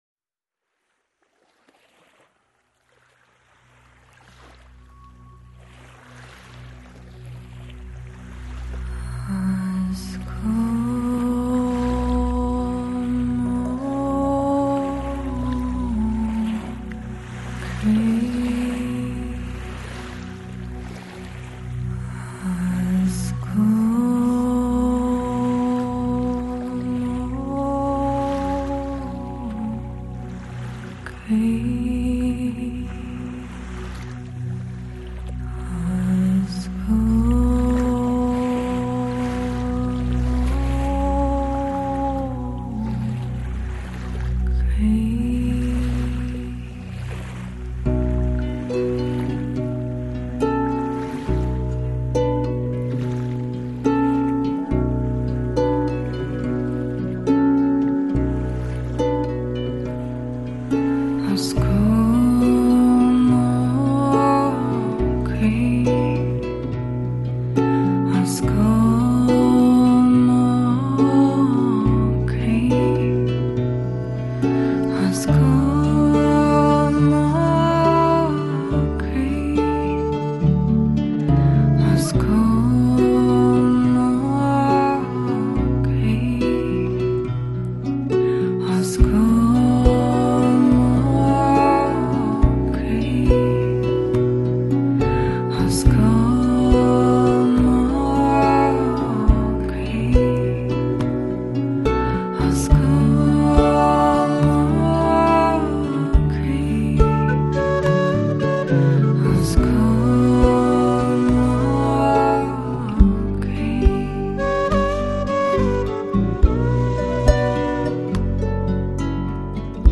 Жанр: New Age